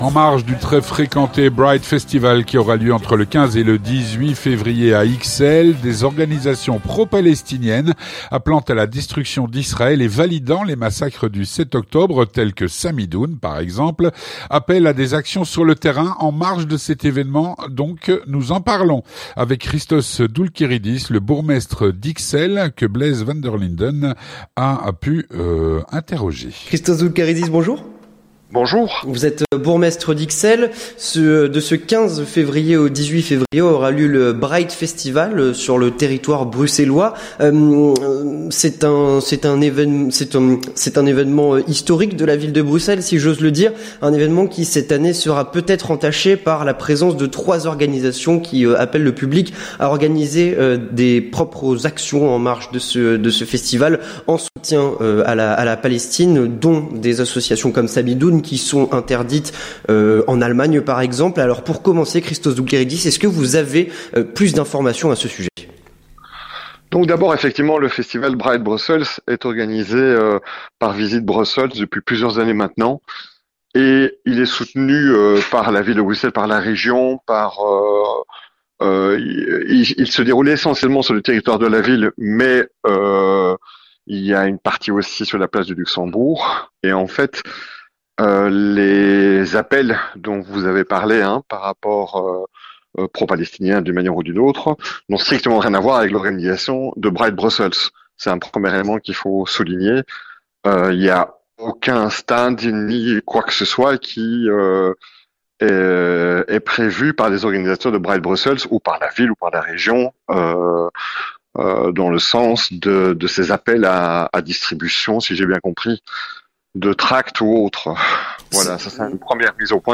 On en parle avec Christos Doulkeridis, bourgmestre d’Ixelles